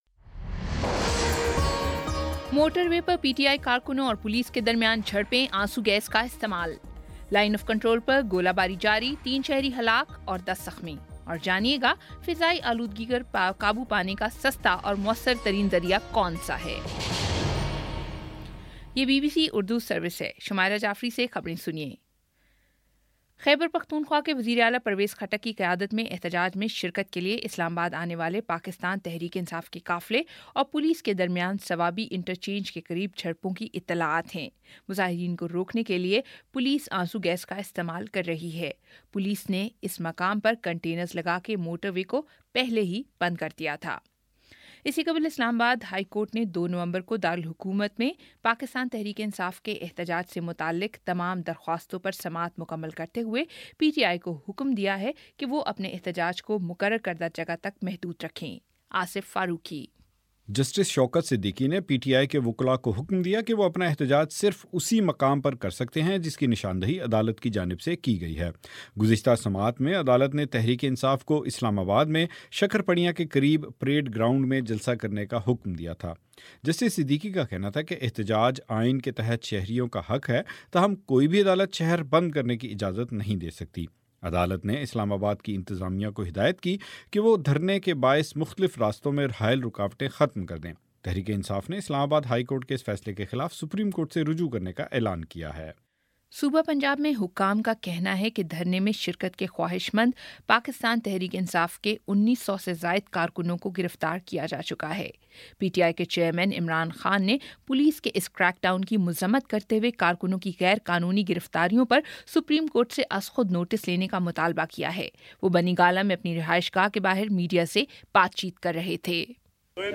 اکتوبر 31 : شام چھ بجے کا نیوز بُلیٹن